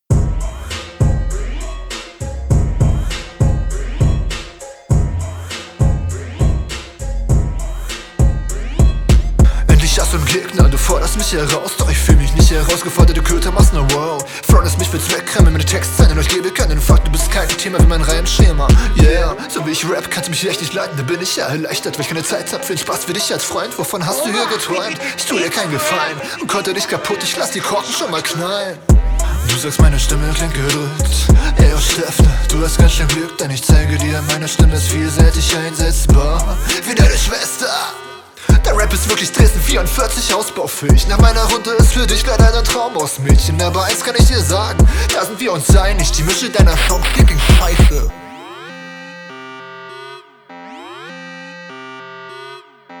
Flowlich kommst du nicht ganz so sauber wie dein Gegner.